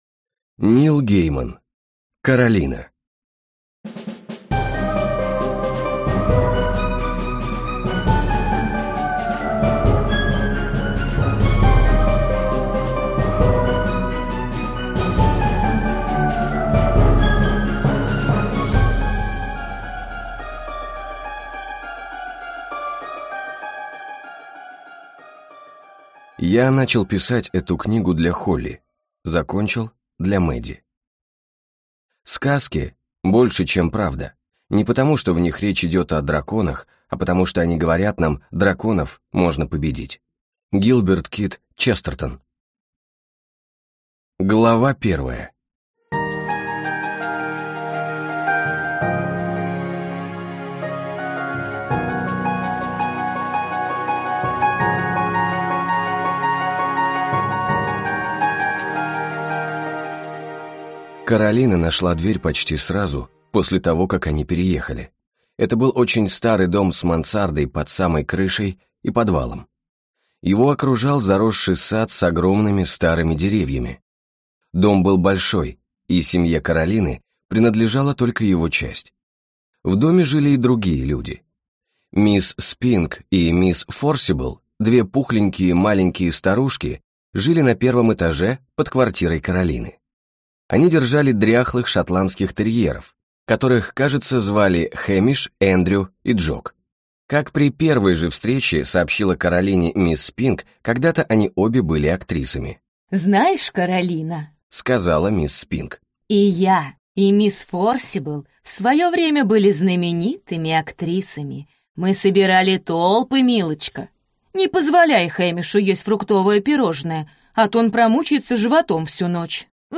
Аудиокнига Коралина - купить, скачать и слушать онлайн | КнигоПоиск